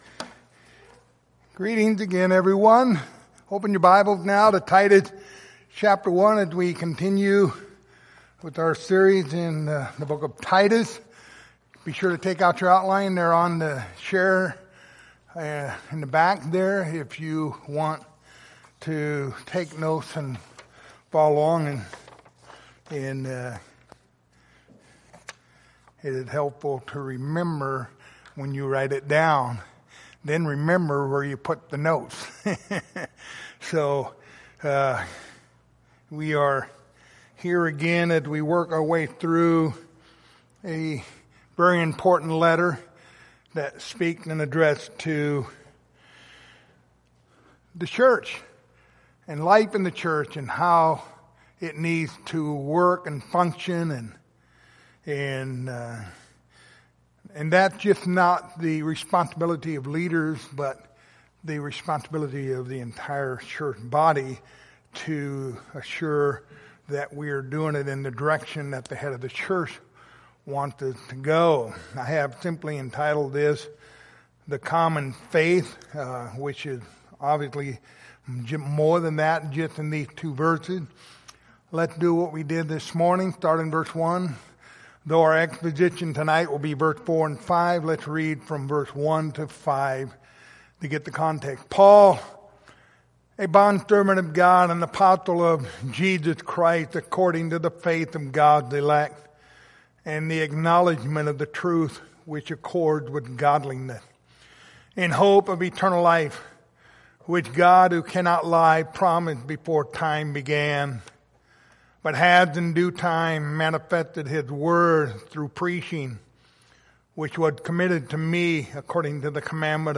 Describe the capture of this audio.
Pastoral Epistles Passage: Titus 1:4-5 Service Type: Sunday Evening Topics